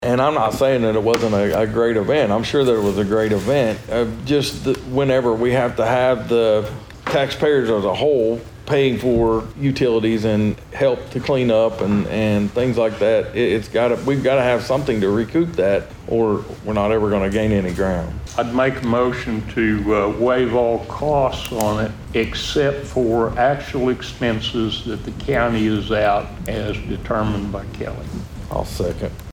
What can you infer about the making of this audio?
Osage Co Commissioners Have Brief Monday Meeting